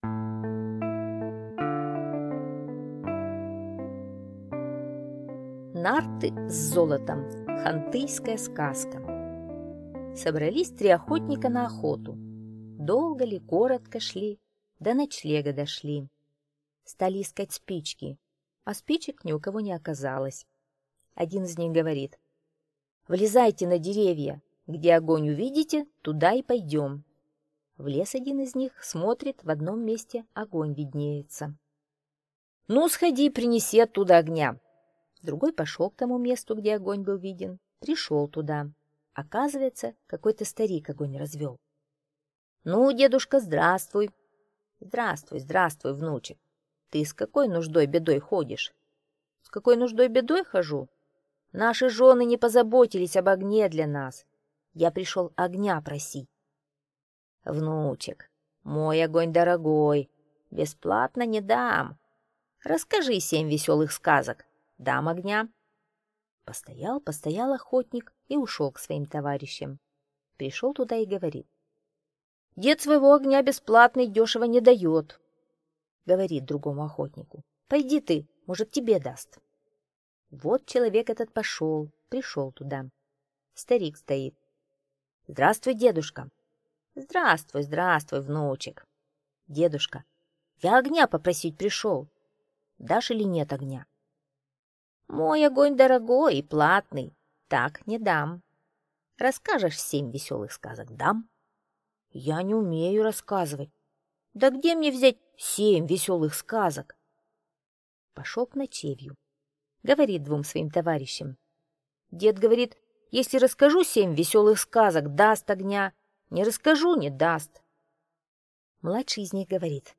Нарты с золотом - хантыйская аудиосказка - слушать онлайн